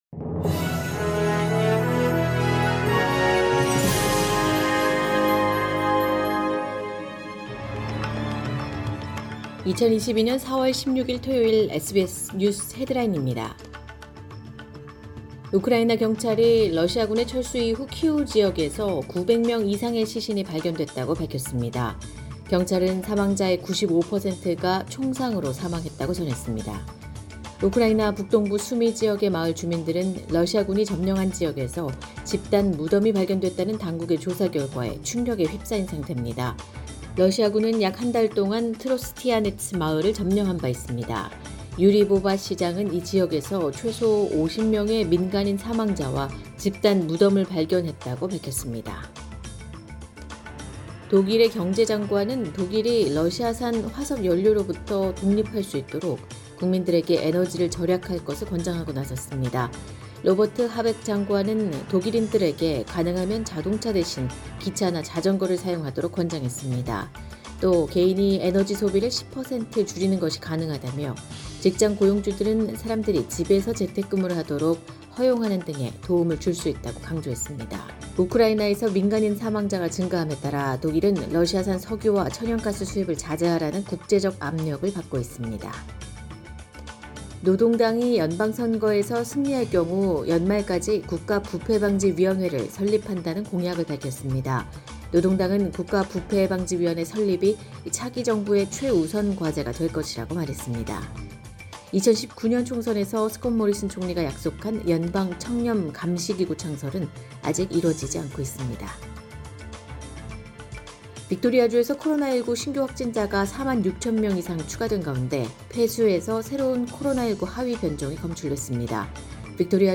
2022년 4월 16일 토요일 SBS 뉴스 헤드라인입니다.